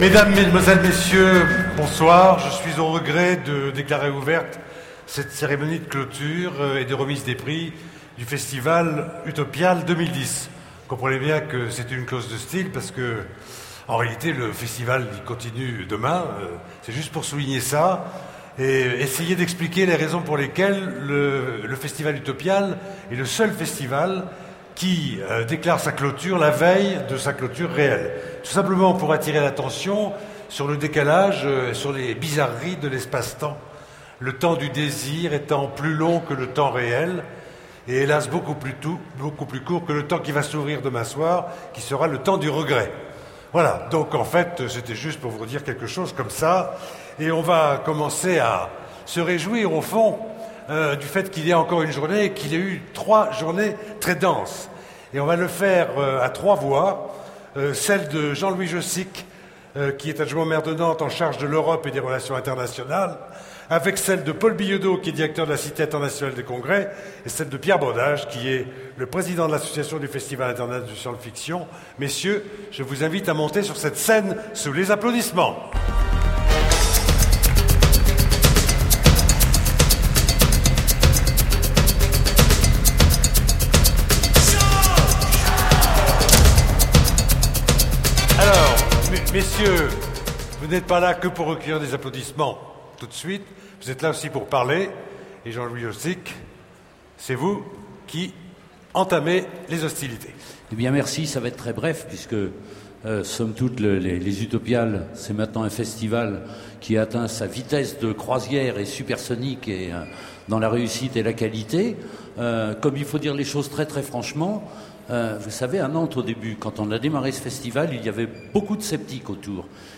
Voici l'enregistrement de la remise du Prix Européen Utopiales des Pays de la Loire.